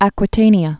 (ăkwĭ-tānē-ə)